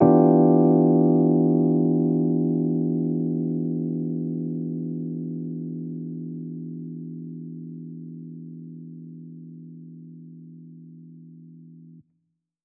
JK_ElPiano2_Chord-Em7b9.wav